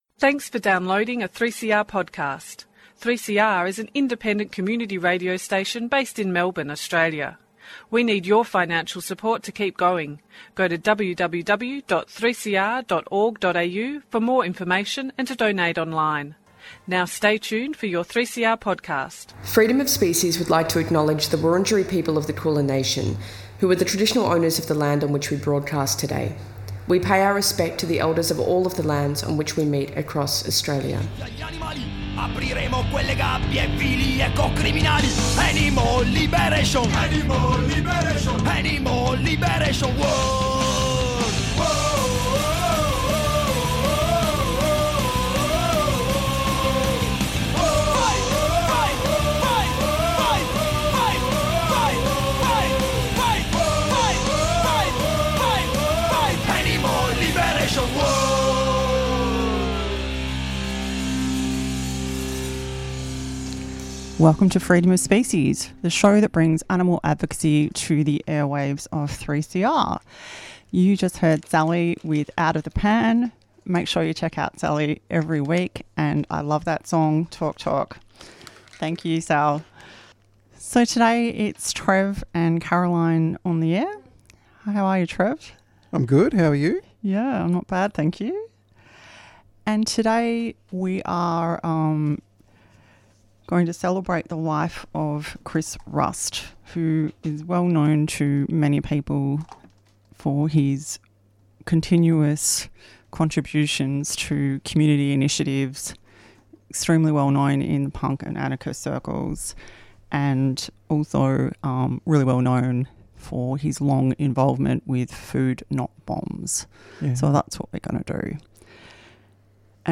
Tweet Freedom of Species Sunday 1:00pm to 2:00pm Animal advocacy on the airwaves, hosted by a team of local animal advocates.